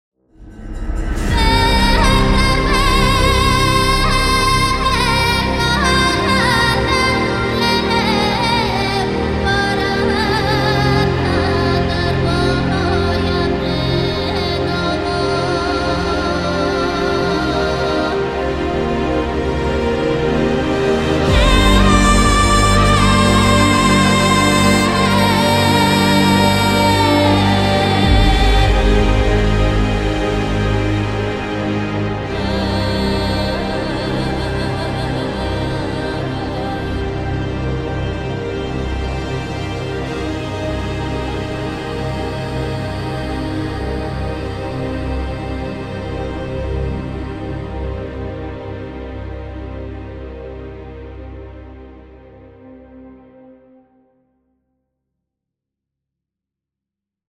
• 由著名民谣歌手演唱，大型保加利亚独唱乐句库
• 以不同拍速与调号录制
独一无二的民谣之声
致力于传承保加利亚民谣那独特的女歌手喉音唱法
在制作时将其采样直接下混至三个麦克风拾音位